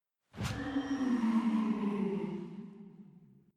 HAUNTING_HOWL.mp3